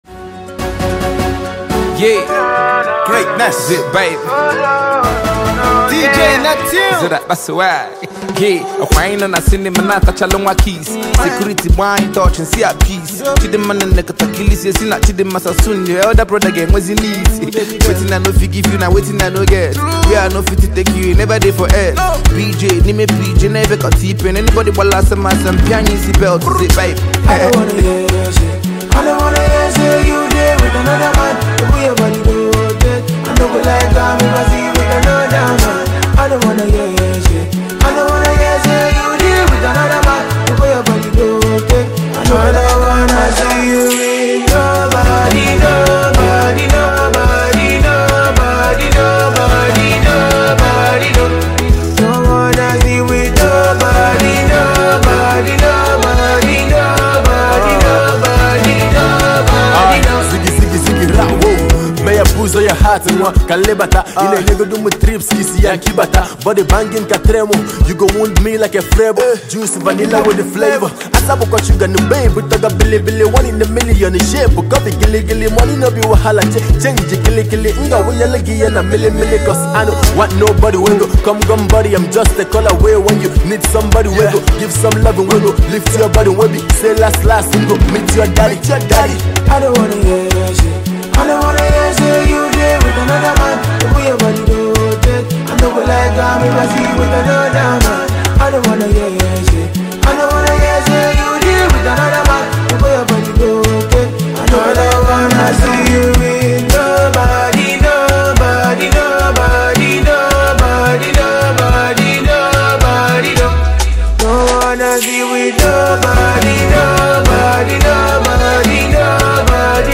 indigenous rappers